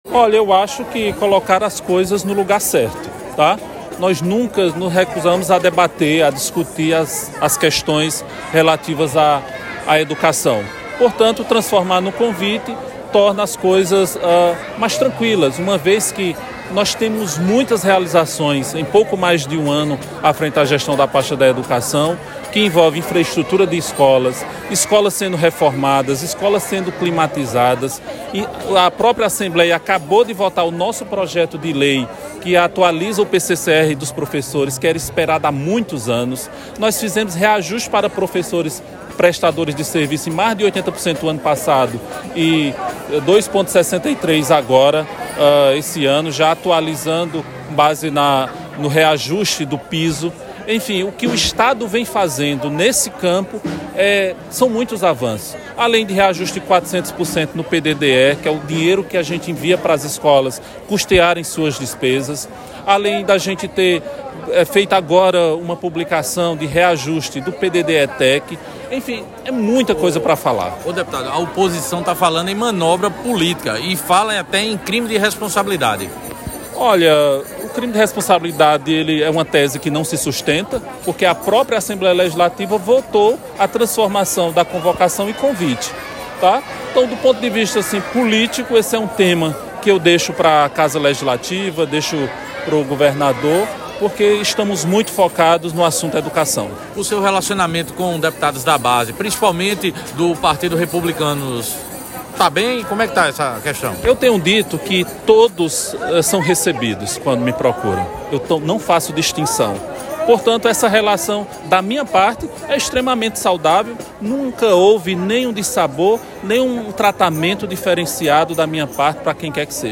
Abaixo a fala do secretário Roberto Souza.